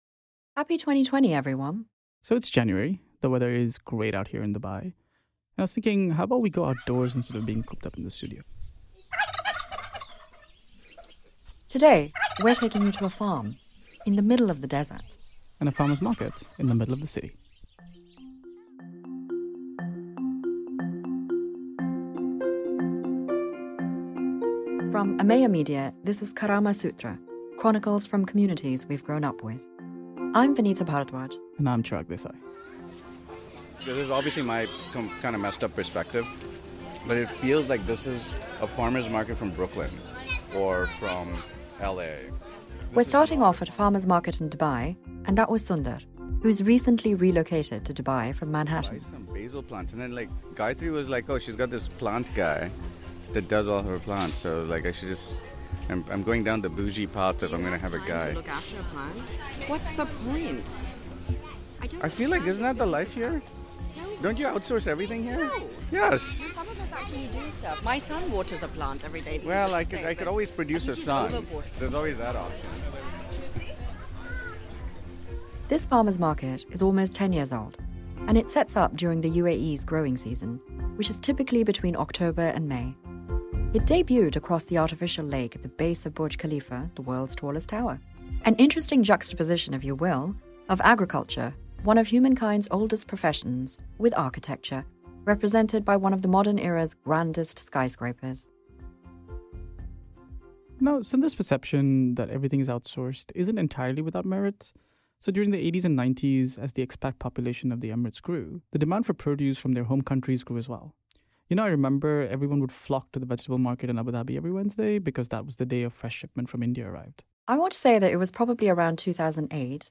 On this episode, we're taking you outdoors to a farmers' market in Dubai and a farm in Abu Dhabi, alongwith a few dispatches from Portugal. Through many voices we try to demystify the special spirit that is unique to farmers and how a market can really bring people together.